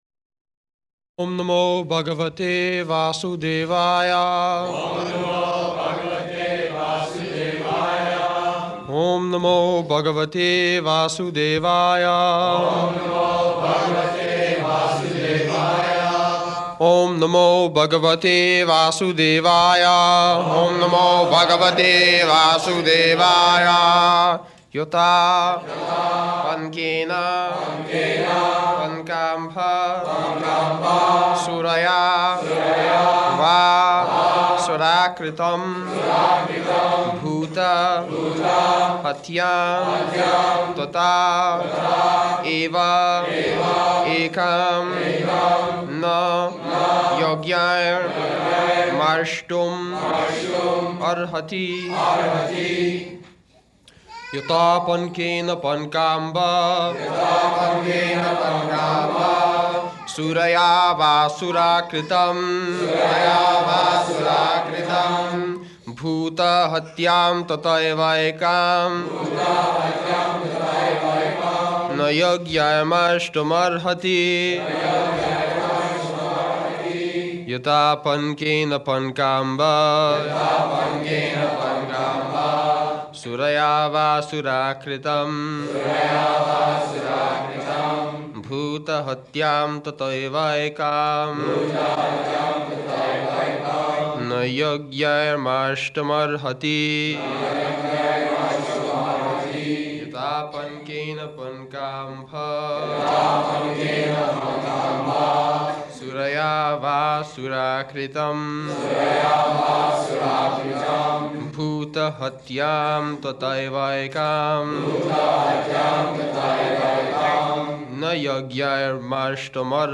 May 14th 1973 Location: Los Angeles Audio file